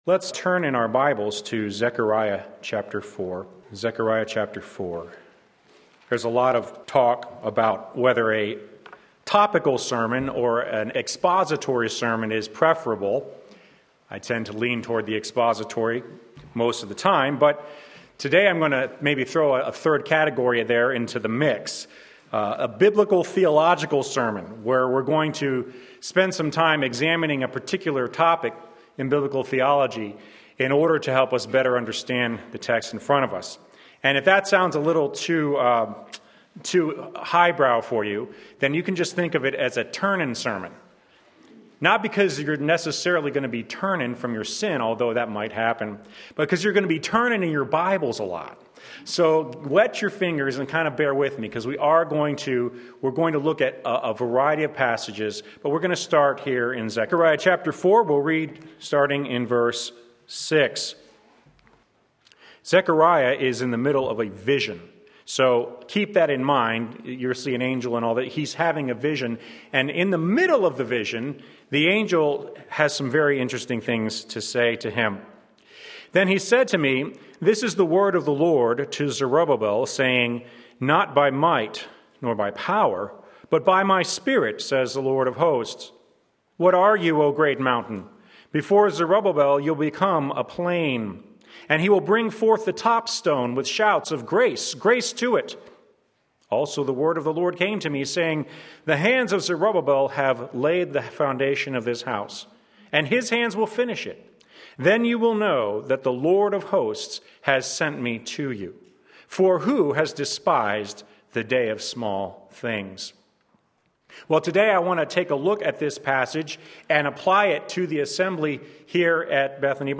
Sermon Link
Building God’s Temple by God’s Power Zechariah 4:6–10a Sunday Morning Service